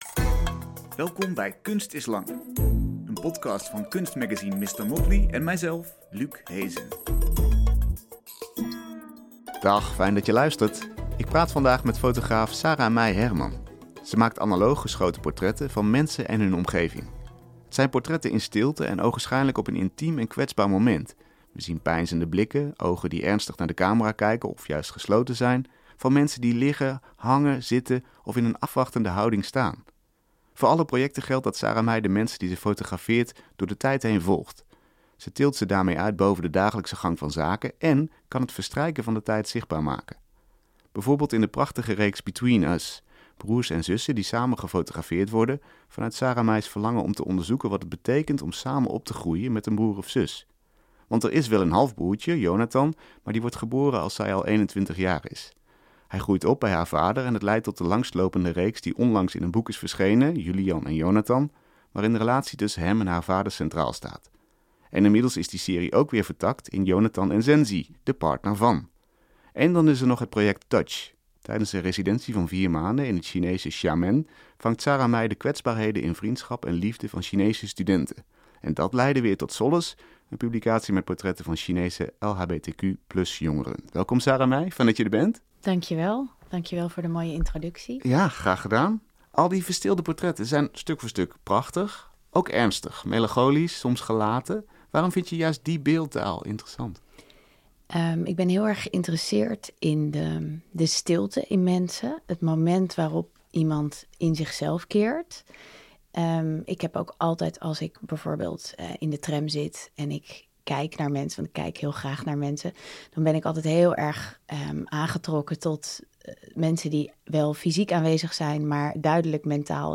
Je hoort elke week een uitgebreid gesprek met een kunstenaar over het laatste werk, inspiratiebronnen en drijfveren.